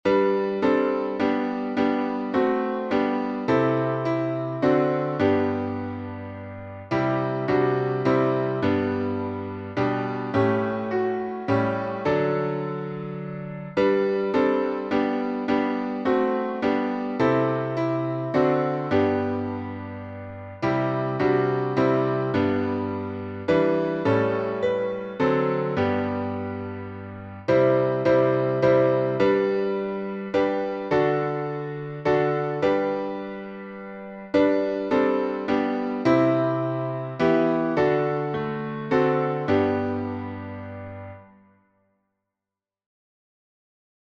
#4046: Faith of Our Fathers — G major, four stanzas | Mobile Hymns
Words by Frederick W. Faber (1814-1863), 1849Tune: ST. CATHERINE by Henri F. Hemy (1818-1888), 1864; arr by James G. Walton (1821-1905)Key signature: G major (1 sharp)Time signature: 3/4Meter: 8.8.8.8.8.8.Public Domain1.